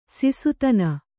♪ sisutana